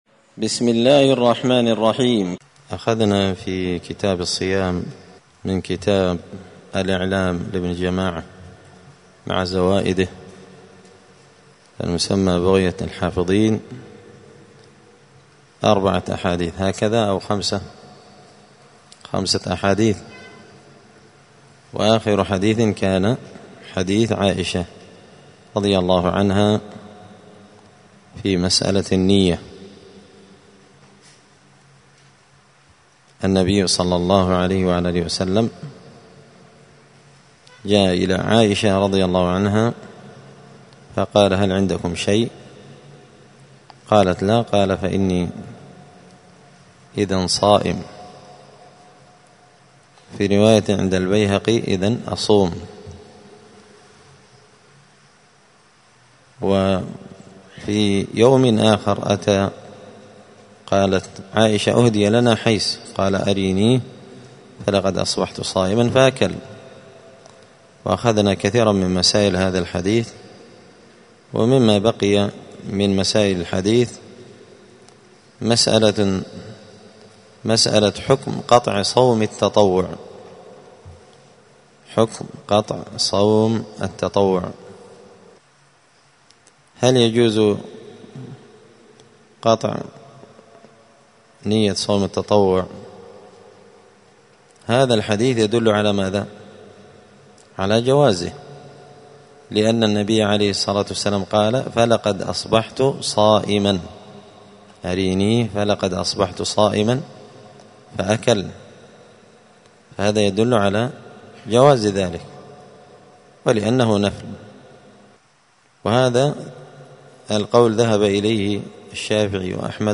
دار الحديث السلفية بمسجد الفرقان بقشن المهرة اليمن
*الدرس الثامن (8) {حكم صوم من أكل أو شرب ناسيا في نهار رمضان…}*